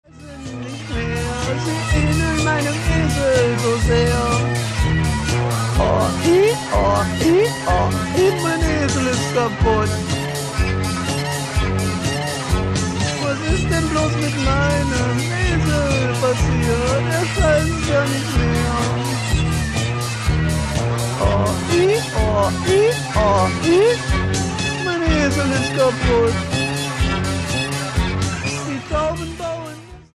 Disc 1 floors it with brutal punk shorts.